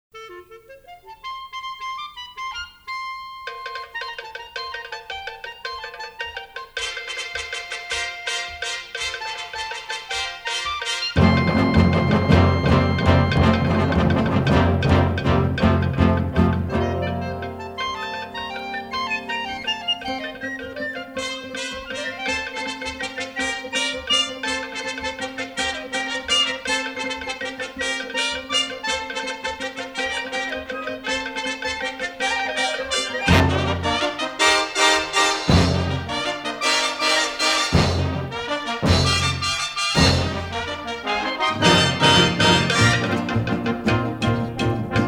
are presented in dynamic stereo